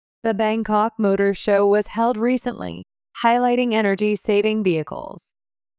１）スロー（前半／後半の小休止あり）